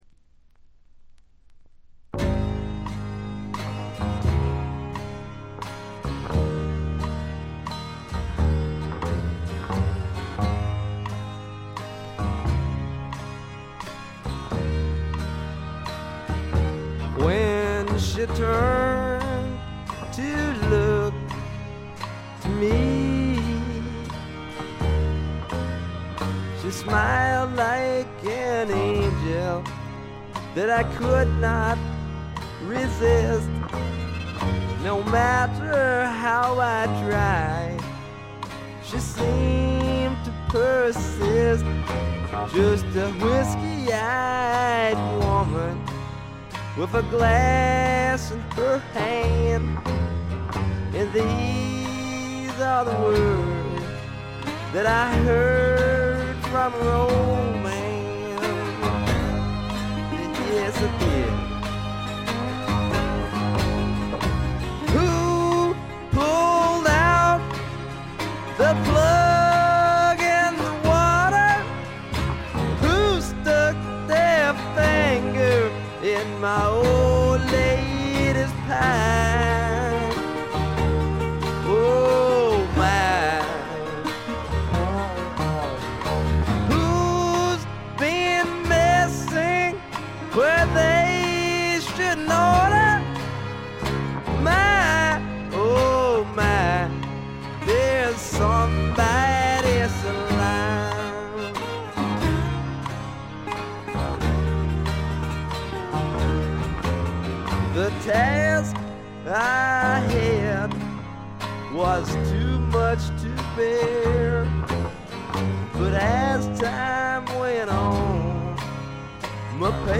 よりファンキーに、よりダーティーにきめていて文句無し！
試聴曲は現品からの取り込み音源です。